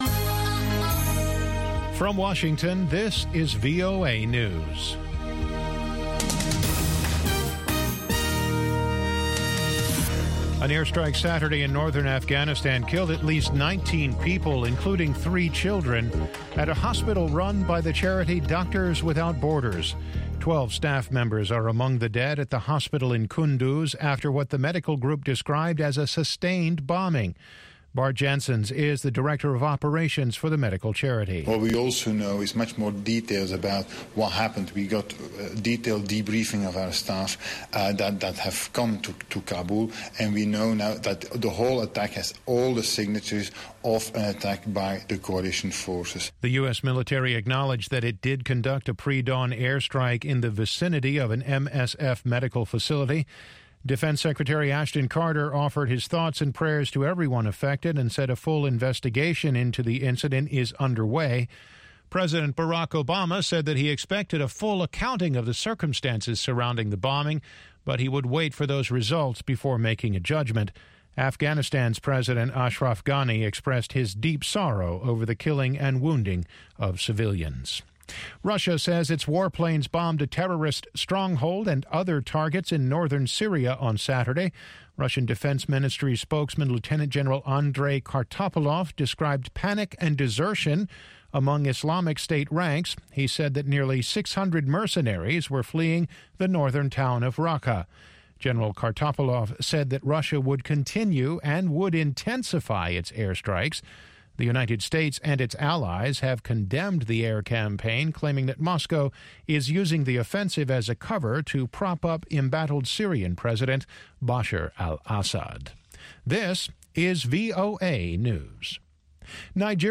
N’dombolo to Benga to African Hip Hop
pan-African music